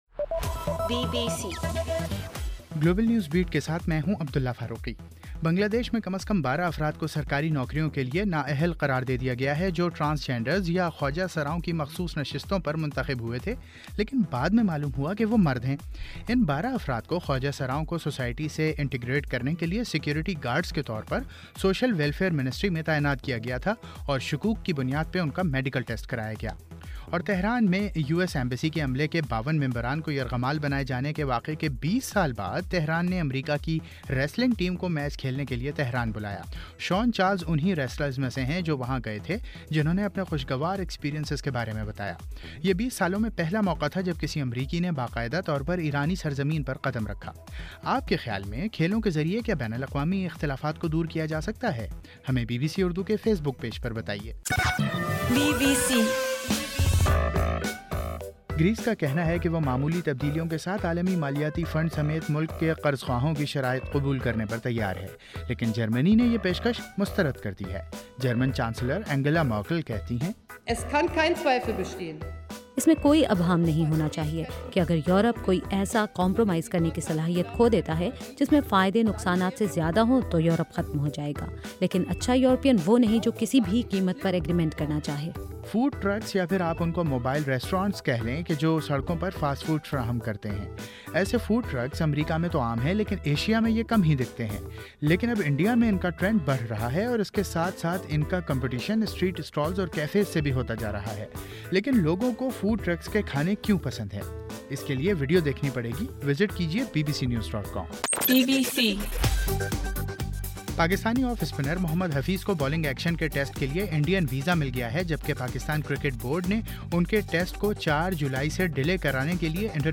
جولائی 1: رات 10 بجے کا گلوبل نیوز بیٹ بُلیٹن